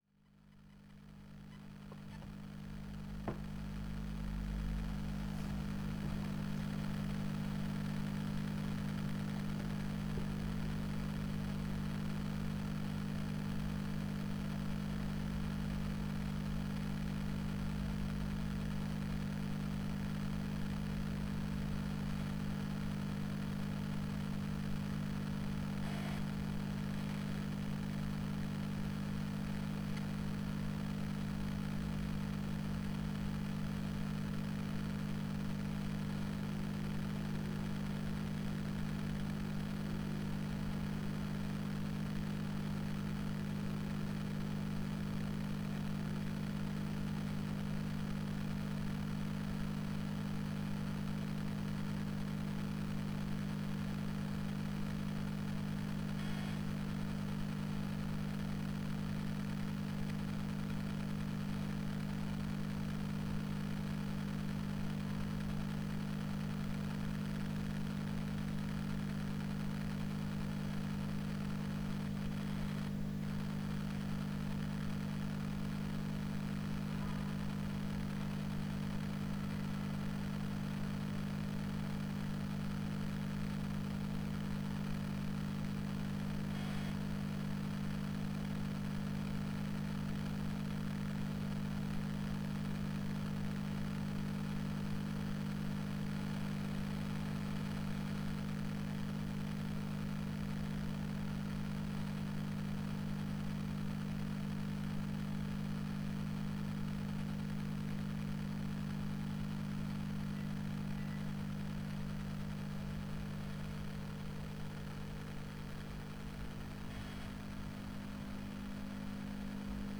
speaker_distortion.wav